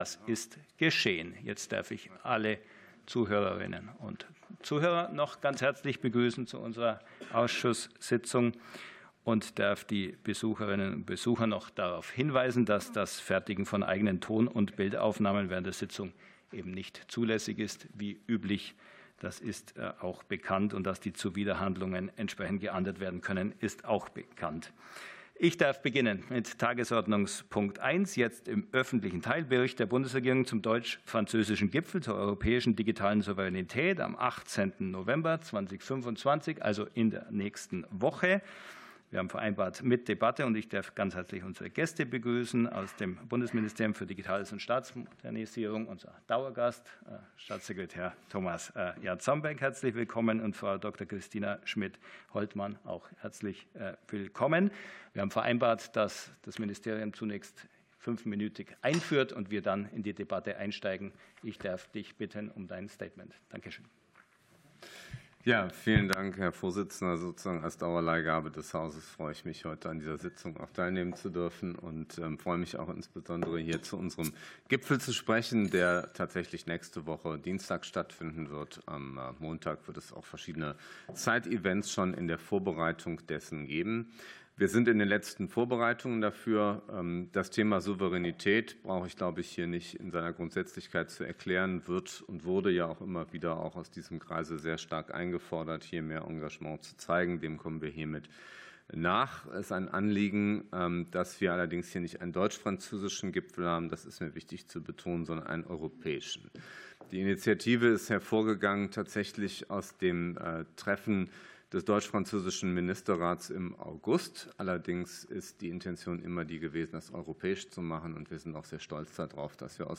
Anhörung des Ausschusses für Digitales und Staatsmodernisierung